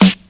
• Original Acoustic Snare Sound G Key 49.wav
Royality free snare one shot tuned to the G note. Loudest frequency: 2758Hz
original-acoustic-snare-sound-g-key-49-kBa.wav